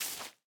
Minecraft Version Minecraft Version latest Latest Release | Latest Snapshot latest / assets / minecraft / sounds / block / big_dripleaf / step6.ogg Compare With Compare With Latest Release | Latest Snapshot
step6.ogg